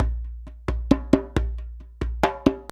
089DJEMB02.wav